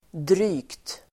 Uttal: [dry:kt]